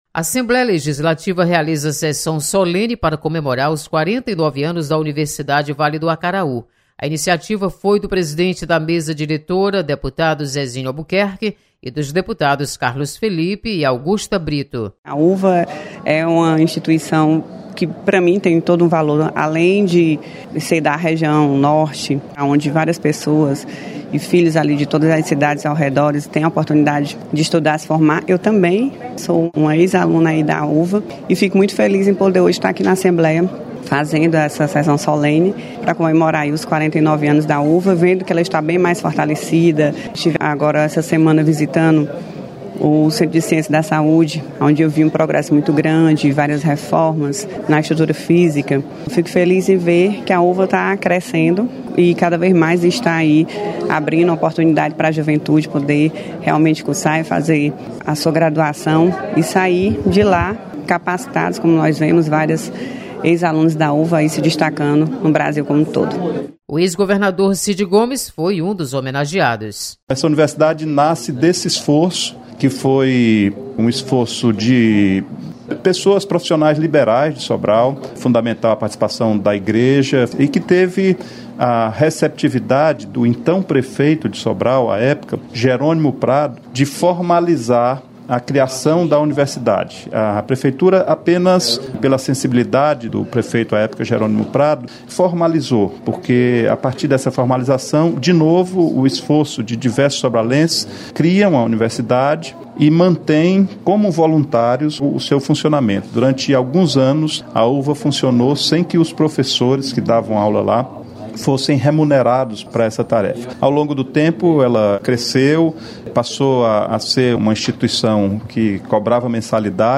Assembleia comemora aniversário da UVA e o Dia Nacional do Vereador. Repórter